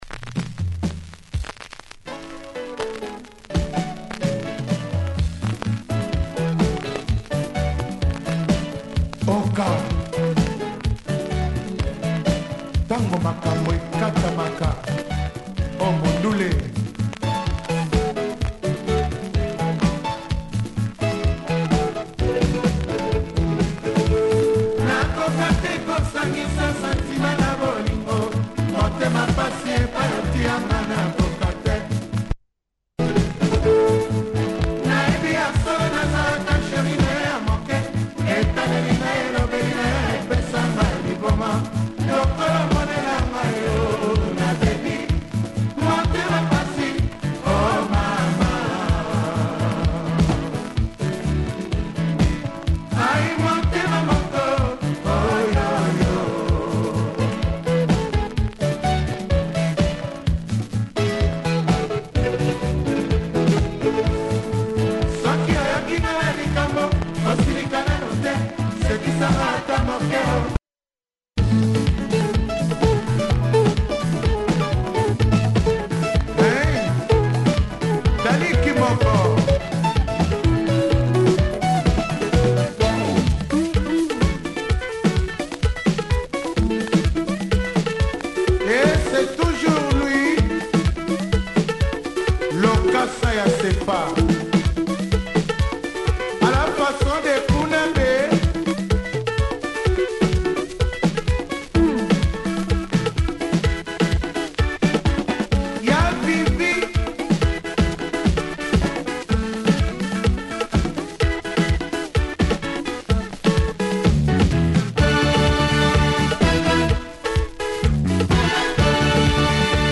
Modern congolese orchestra